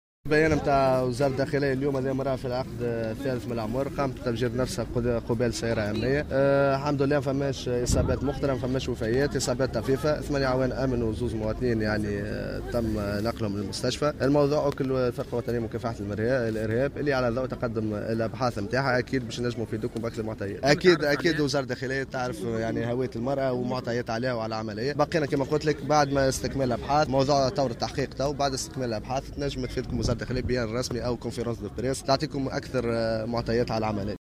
التفجير الإرهابي لم يكن عن بعد وهذه حالة المصابين (تصريح